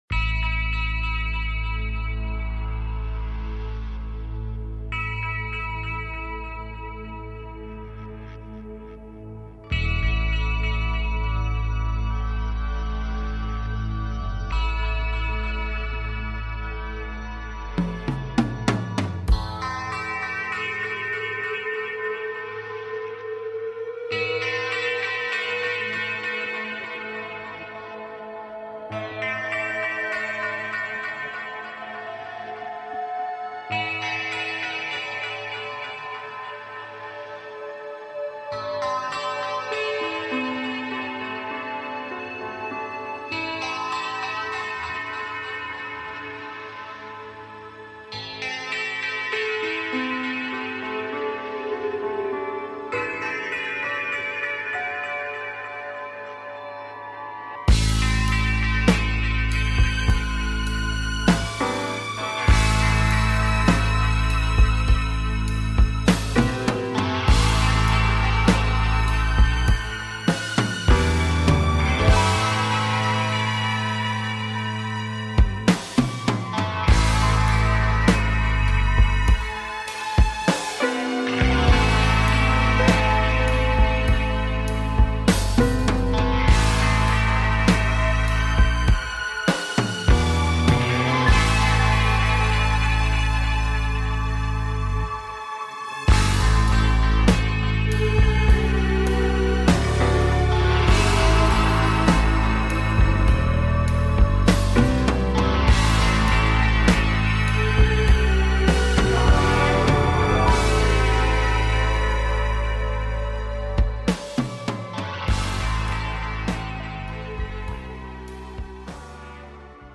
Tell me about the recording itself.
A show based on Timelines and manifesting Timelines while taking caller questions.